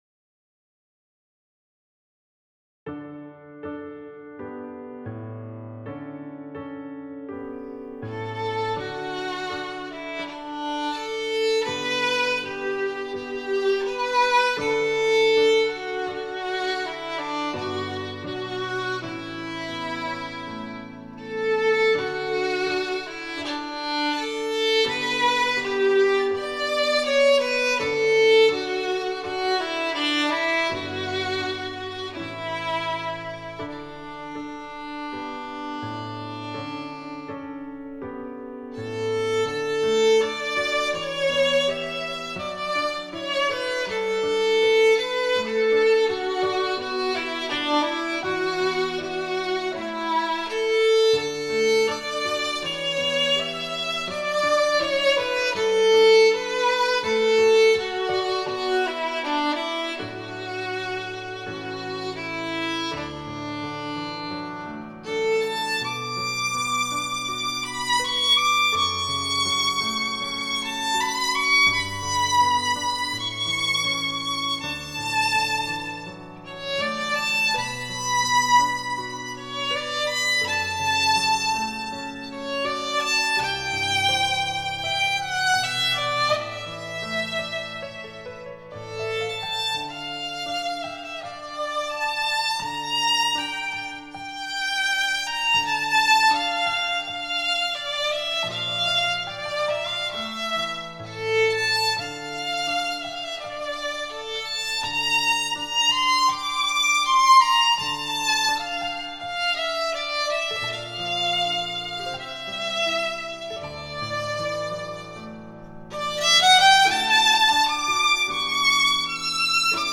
Intermediate Violin Solos for Thanksgiving